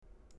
Ich muss sagen, das war der schönste Mausklick den ich jemals gehört habe.